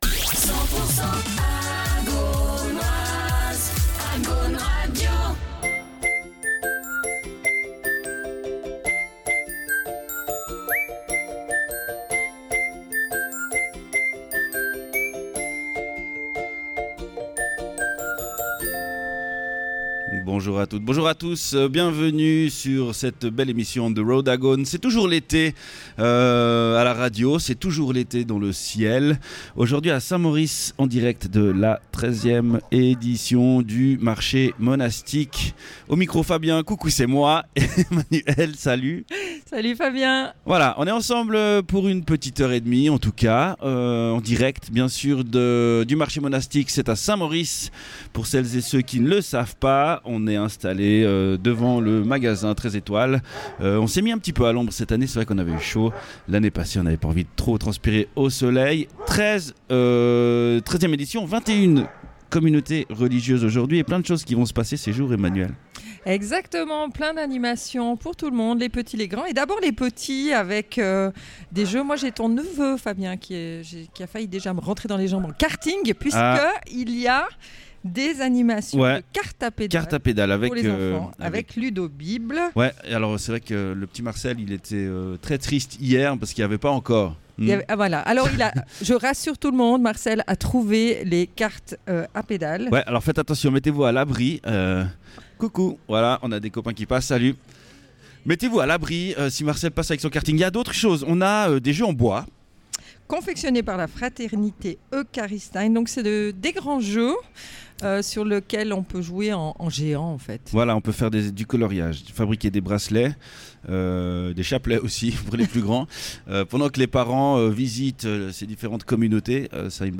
En direct du Marché Monastique de Saint-Maurice, le samedi 20 septembre 2025.